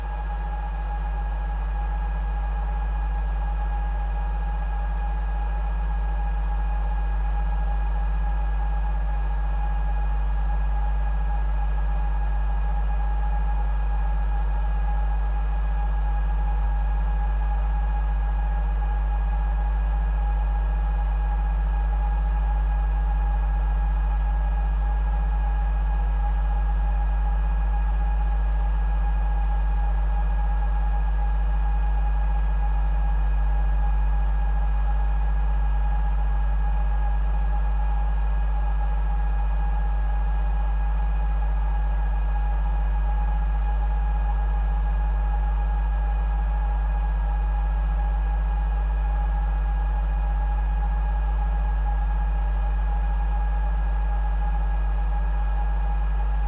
Server room hum.mp3